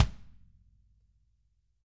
Index of /90_sSampleCDs/ILIO - Double Platinum Drums 2/Partition A/REMO KICK D